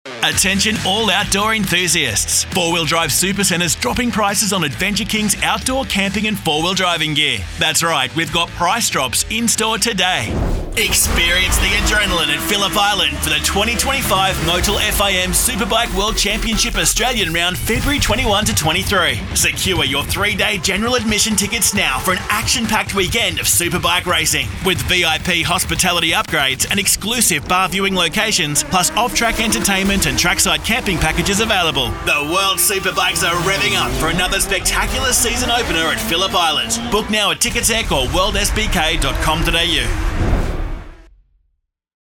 Young and fun, sophisticated and natural, pulled back and versatile….  he’s good to go when you’re ready!
• Hard Sell